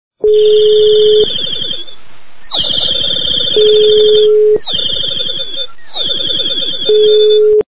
» Звуки » Природа животные » Попугай - Пение
При прослушивании Попугай - Пение качество понижено и присутствуют гудки.
Звук Попугай - Пение